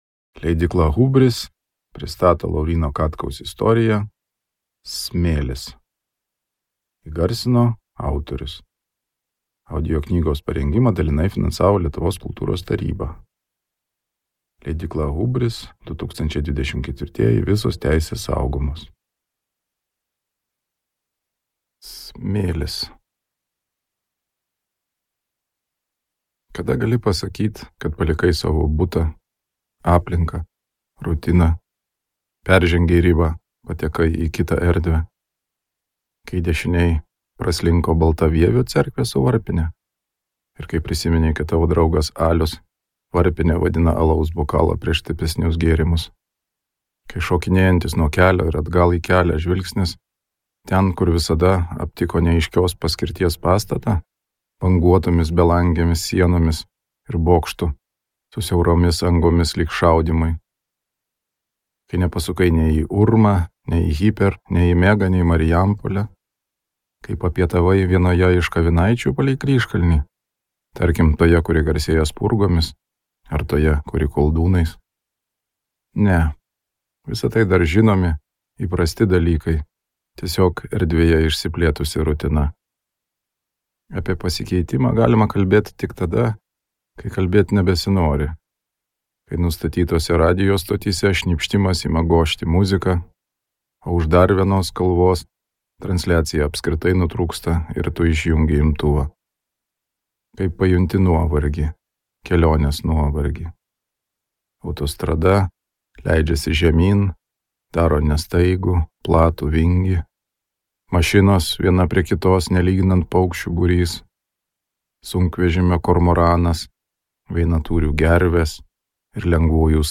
Smėlis | Audioknygos | baltos lankos